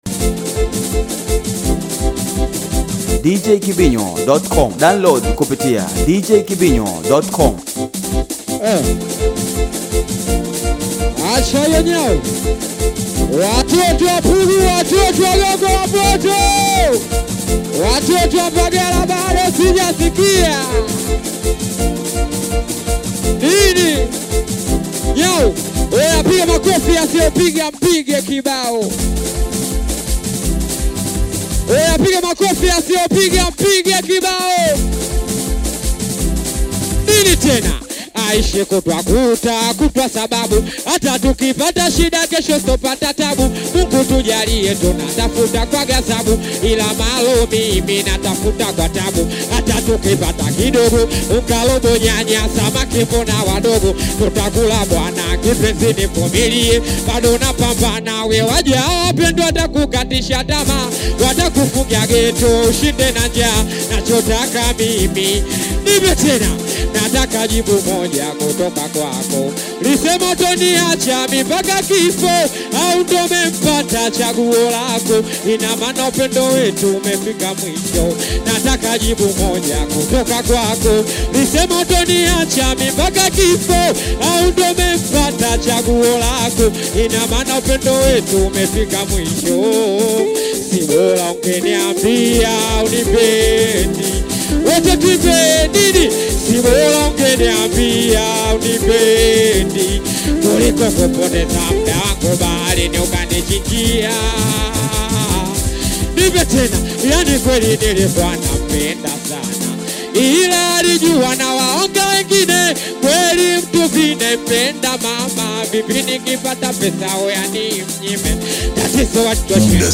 Hii ni Show Live nyingine